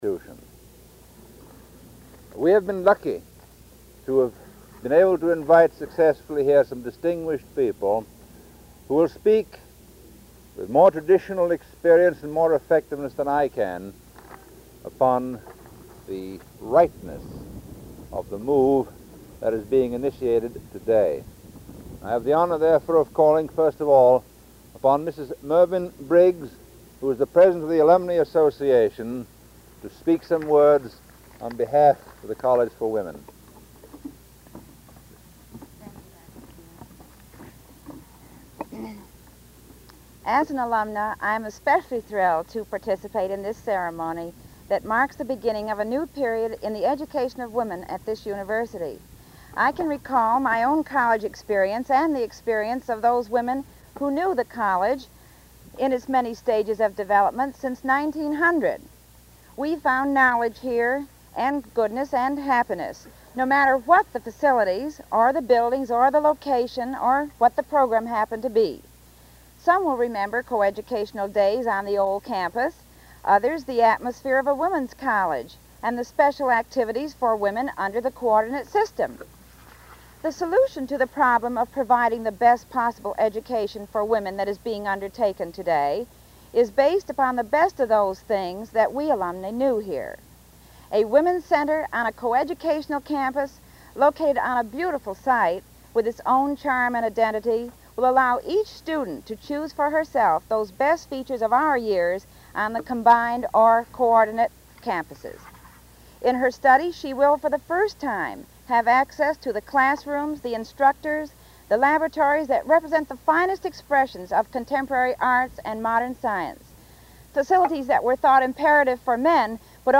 1952 Groundbreaking for Susan B. Anthony Hall and Spurrier Gymnasium
Remarks